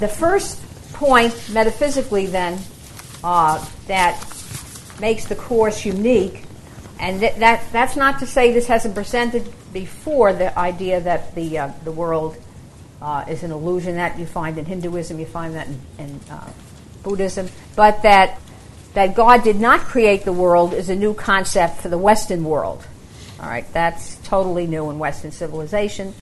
This workshop presents the Course as unique among the spiritualities of the world.